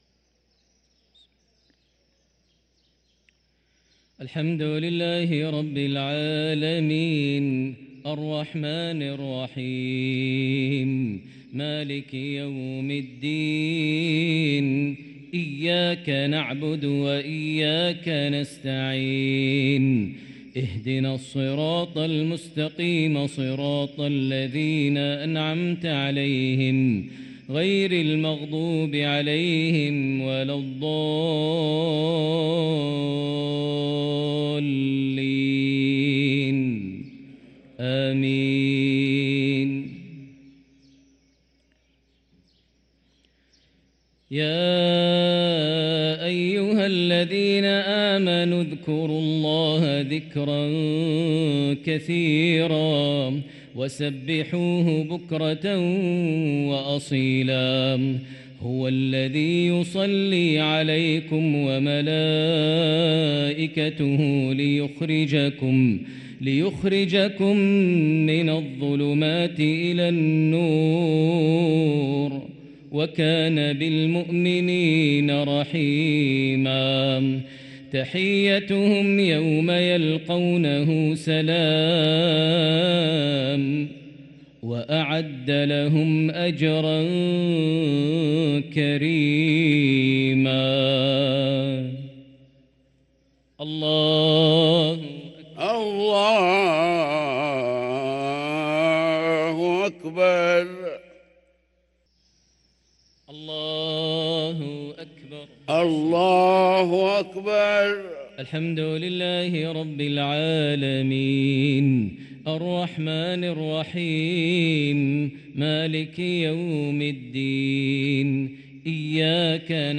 صلاة المغرب للقارئ ماهر المعيقلي 3 جمادي الآخر 1445 هـ
تِلَاوَات الْحَرَمَيْن .